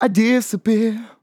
Categories: Vocals Tags: Disappear, dry, english, I, LOFI VIBES, LYRICS, male, sample
MAN-LYRICS-FILLS-120bpm-Am-20.wav